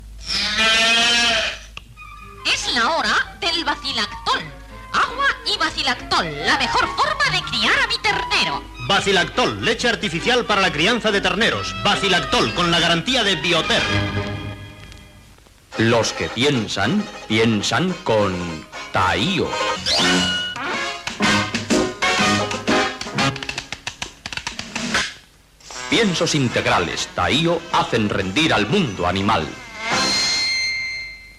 Publcitat de Bacilactol i Piensos Integrales Taiyo Gènere radiofònic Publicitat